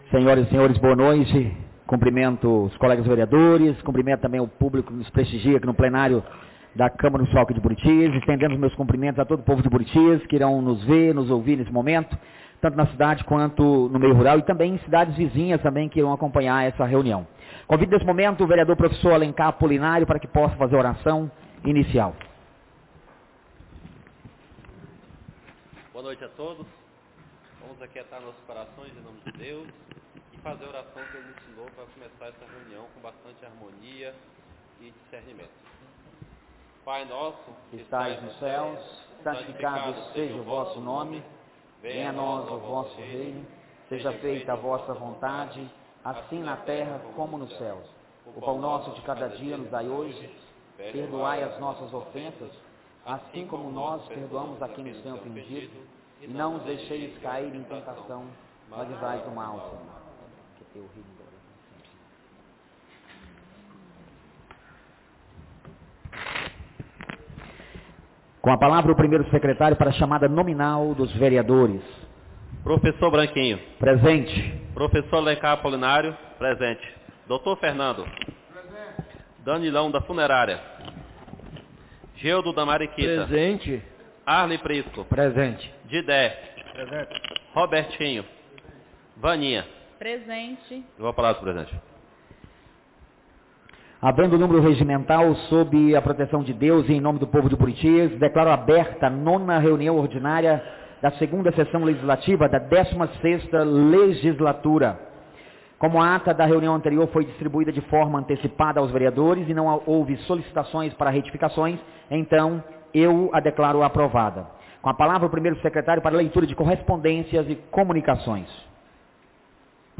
9ª Reunião Ordinária da 2ª Sessão Legislativa da 16ª Legislatura - 30-03-26